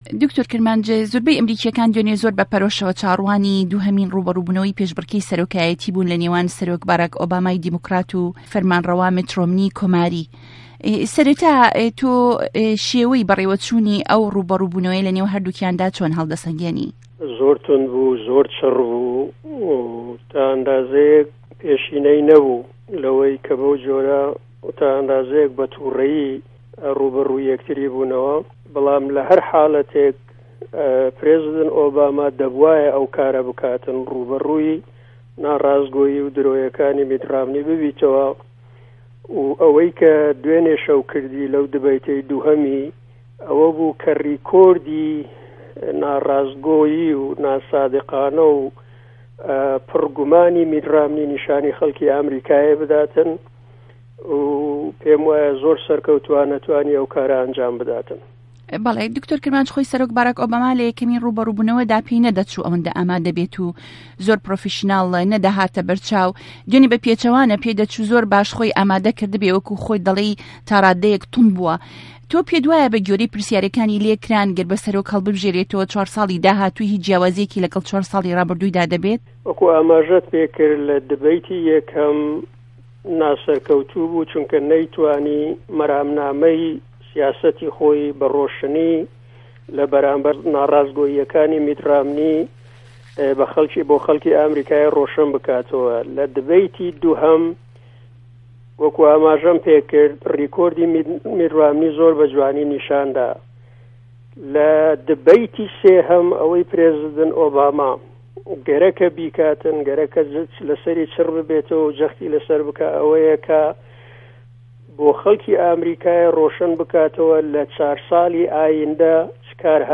گفتوگۆ